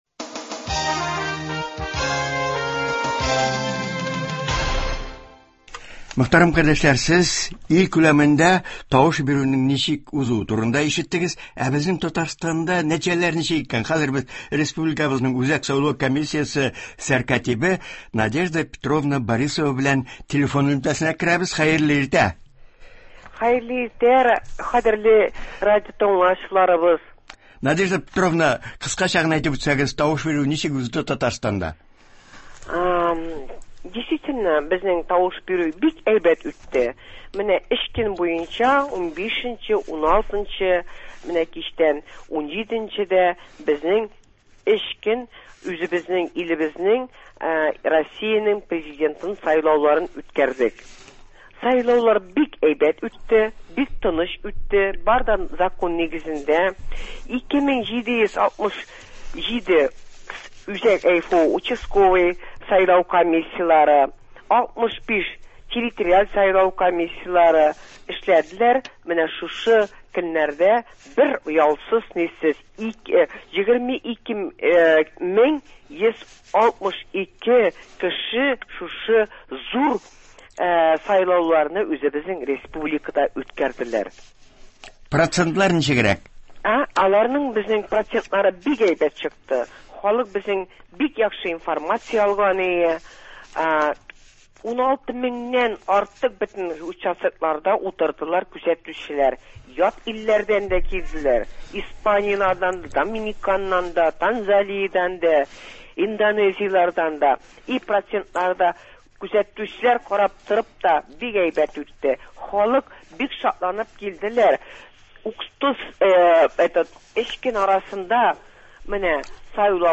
Тапшыру җомга- якшәмбе көннәрендә үткәрелгән Россия Президентын сайлау нәтиҗәләренә багышлана:телефон элемтәсе аша Татарстан үзәк сайлау комиссиясе